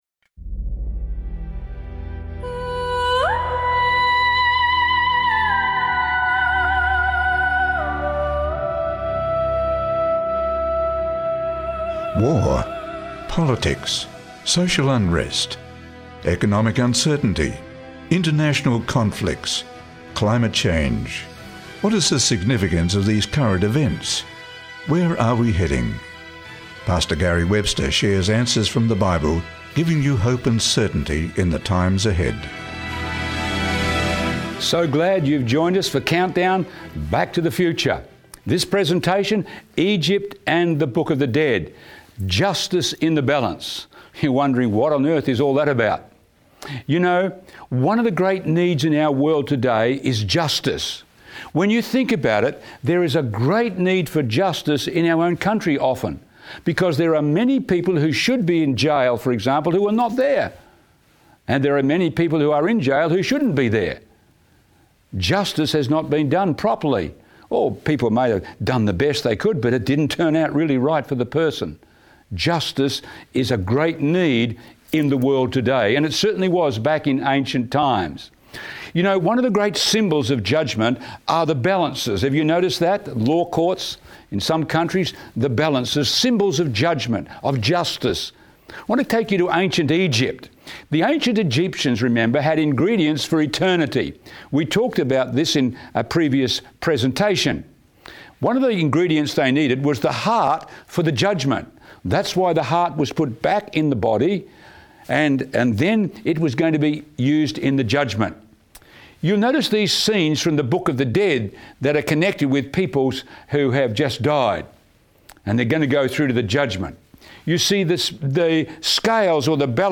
Join us as we explore the profound themes of justice, judgment, and the cleansing of the heavenly sanctuary in this captivating presentation. Discover the ancient Egyptian beliefs surrounding the Book of the Dead and the scales of judgment, and how they foreshadow the ultimate judgment that will take place in the heavenly temple. Uncover the hidden meaning behind the mysterious 2,300-day prophecy in the book of Daniel, and learn how it points to the beginning of God's final judgment.